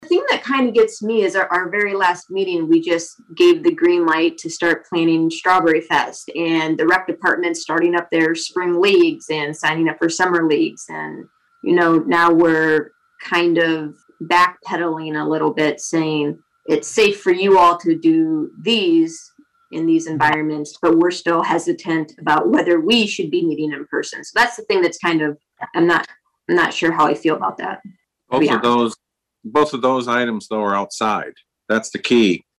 The discussion included this exchange between Council Members Emily Rissman and Randall Hazelbaker.